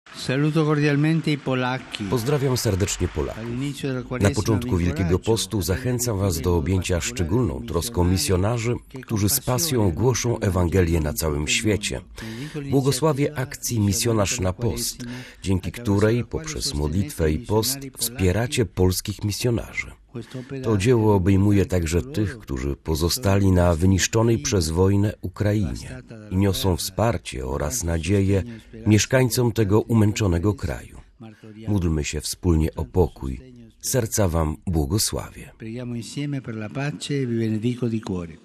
Na progu Wielkiego Postu Ojciec Święty zachęcił Polaków do objęcia szczególną troską misjonarzy. W czasie audiencji środowej pobłogosławił prowadzonej już po raz dziesiąty akcji „Misjonarz na Post”.